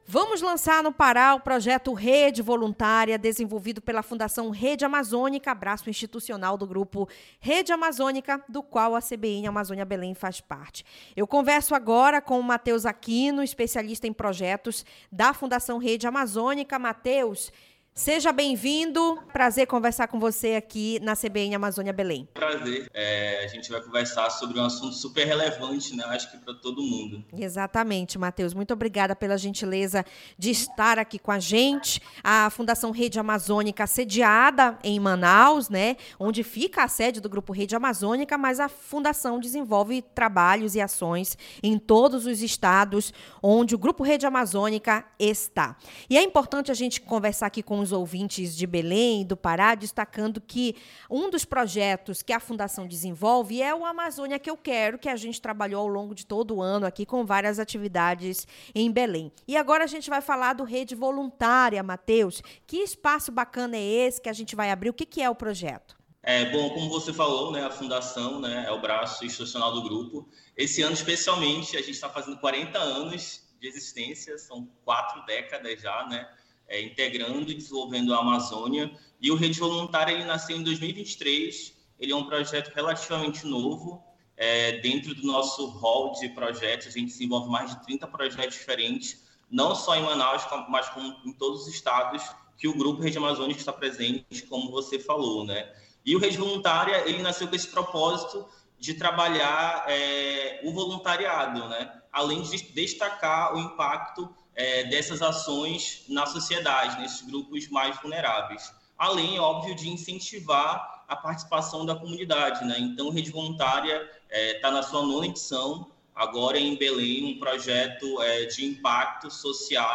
2--ENTREVISTA-REDE-VOLUNTRIA-FRAM.mp3